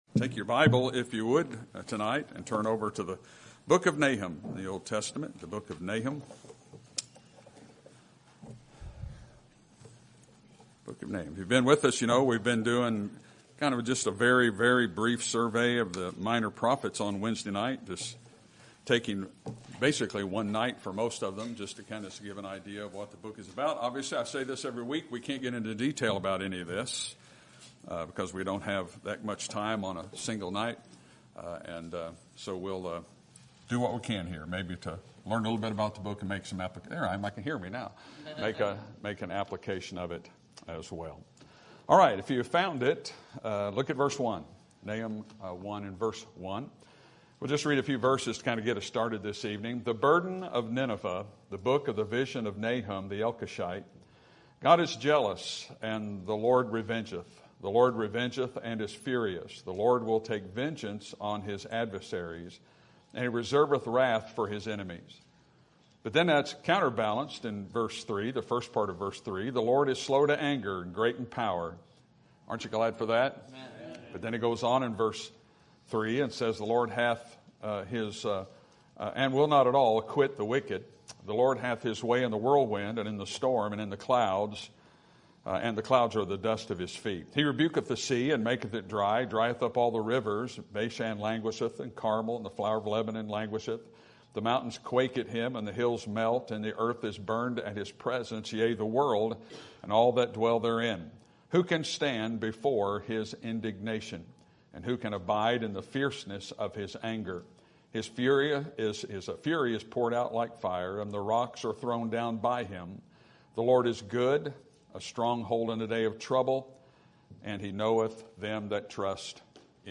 Sermon Topic: Minor Prophets Sermon Type: Series Sermon Audio: Sermon download: Download (23.66 MB) Sermon Tags: Nahum Judgement Grace Fear